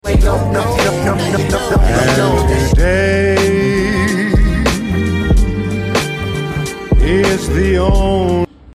Porsche 964 Carrera 4 1991 sound effects free download